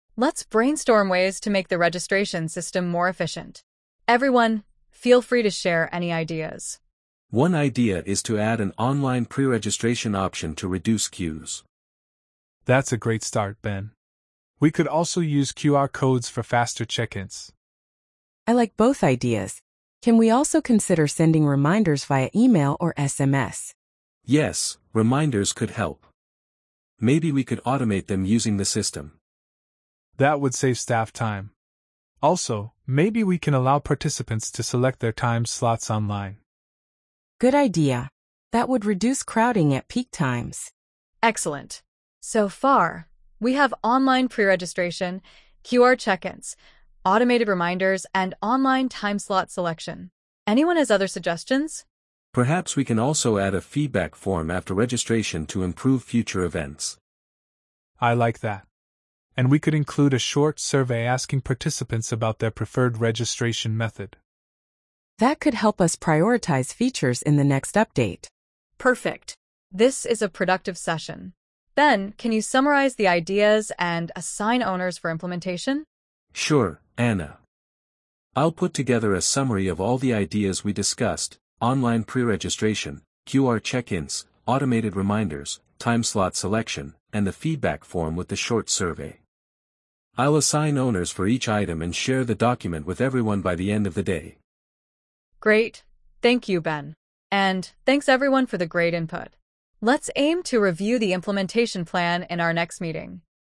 🤝 The team brainstorms ideas to make the registration system faster and more efficient.